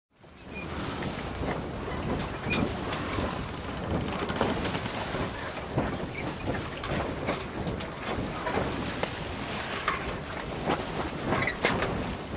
boat.ogg